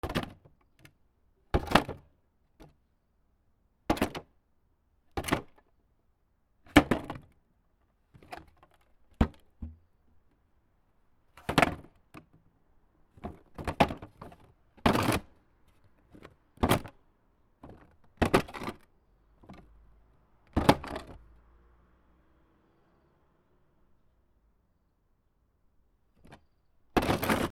木の箱